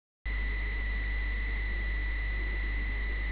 tu as un yamkumo , alors dit moi stp si tu as un bruit etrange entre autre lorsuque tu fait surgir sous word le menu contxtuel.. voici ce son :